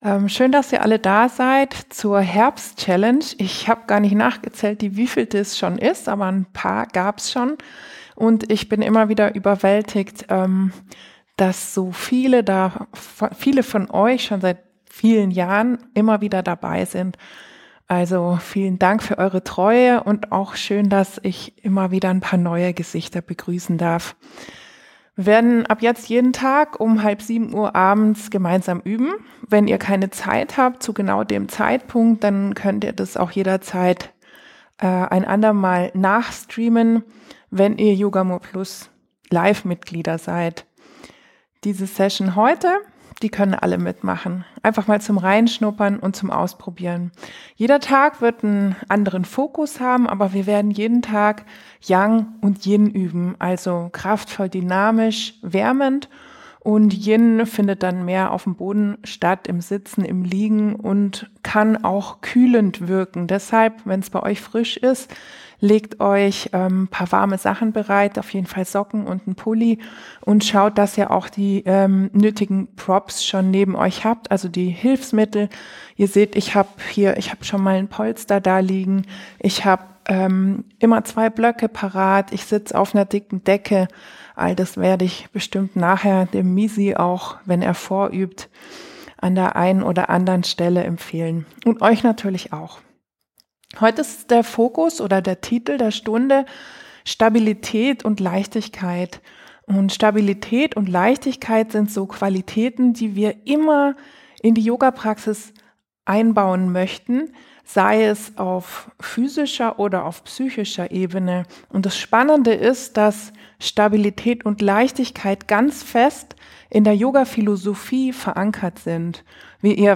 Live Sessions: Stabilität & Leichtigkeit.